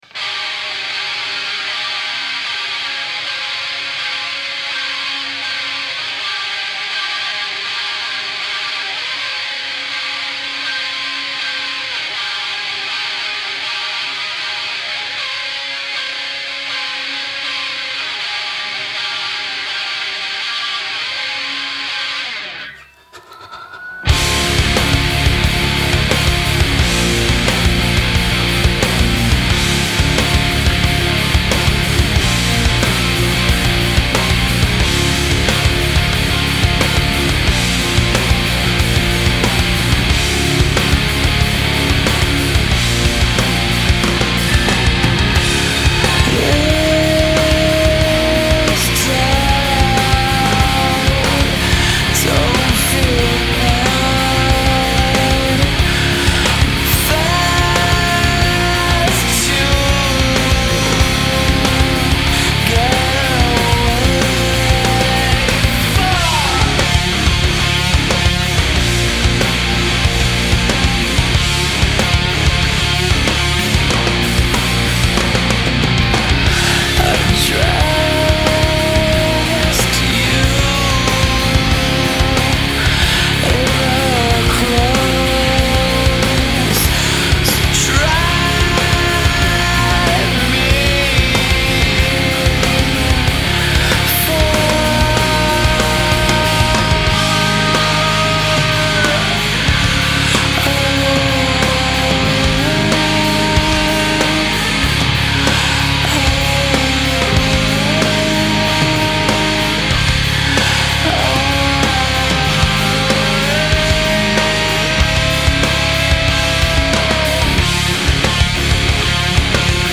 90s alt-metal